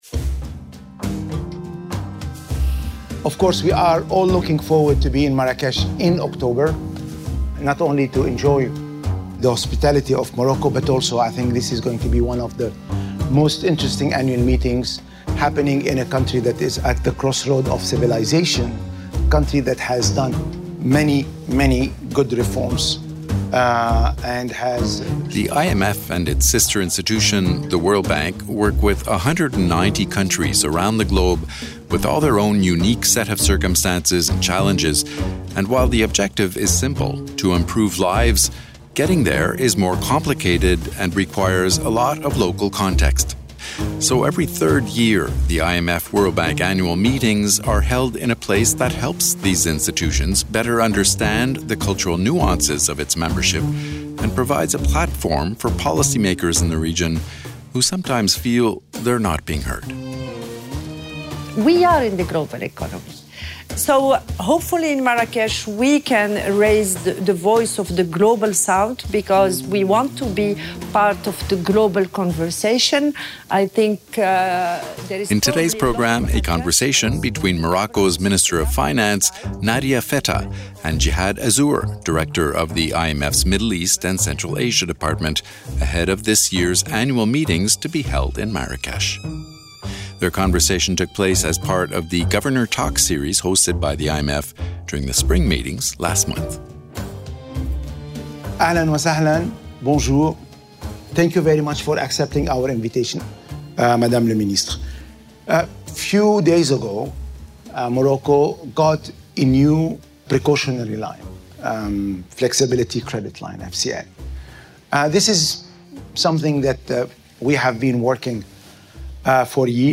But the country managed well thanks to an aggressive reform agenda. In this podcast, Morocco’s Finance Minister Nadia Fettah, and IMF Regional Director Jihad Azour, discuss how Morocco is keeping reforms on track despite the challenging circumstances, and what lessons other countries in the region might learn from Morocco’s experience.
Their conversation was part of the Governor Talks series hosted by the IMF during the IMF-World Bank Spring Meetings, and a preamble to the Annual Meetings to be held in Marrakesh this fall.